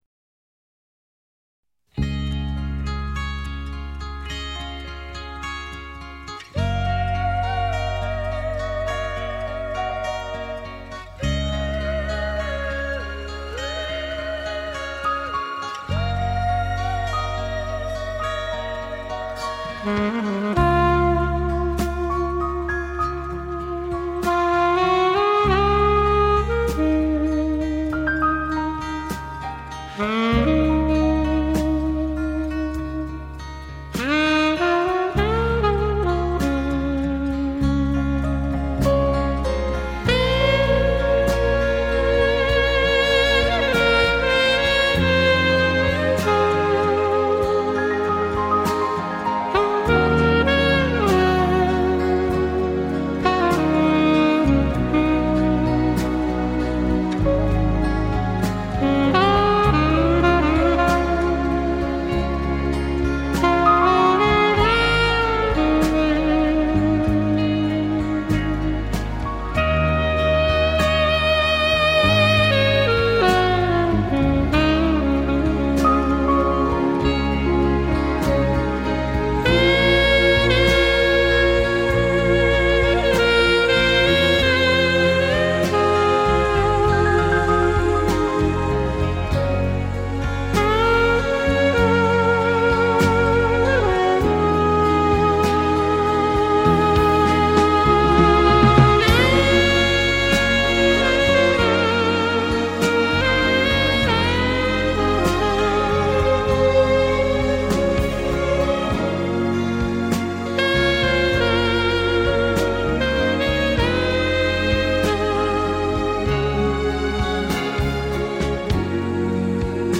萨克斯曲